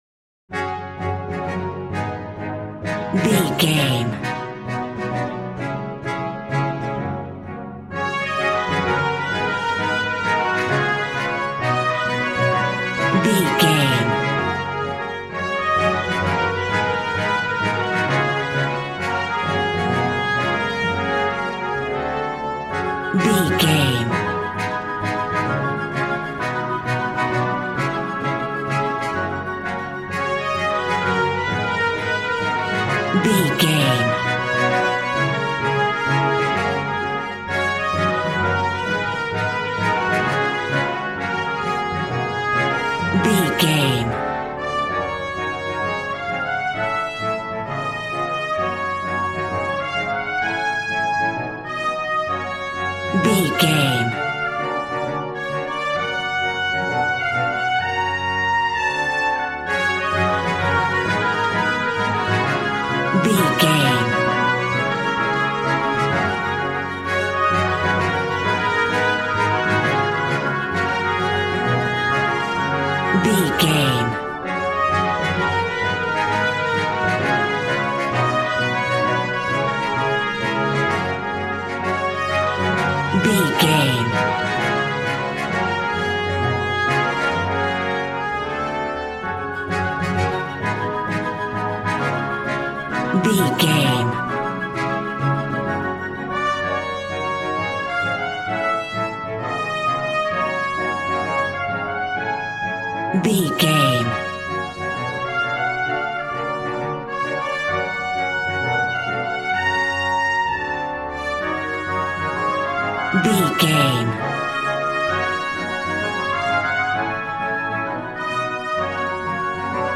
Ionian/Major
G♭
dramatic
epic
percussion
violin
cello